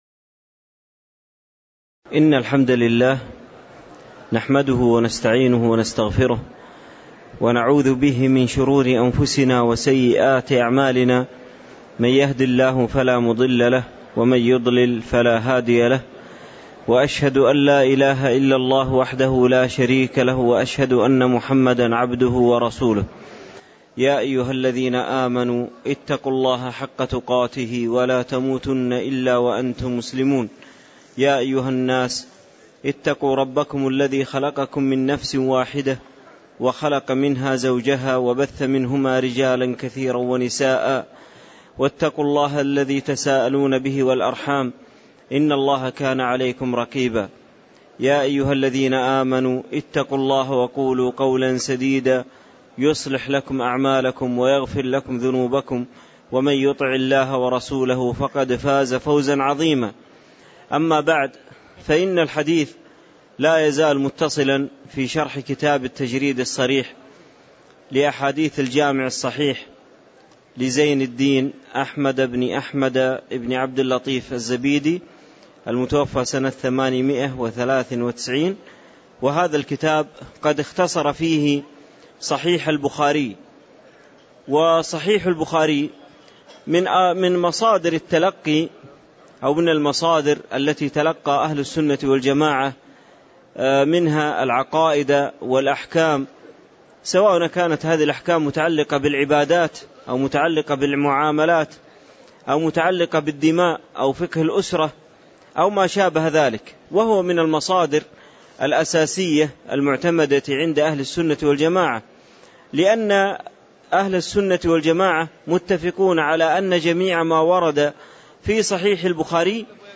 تاريخ النشر ٣٠ ذو الحجة ١٤٣٧ هـ المكان: المسجد النبوي الشيخ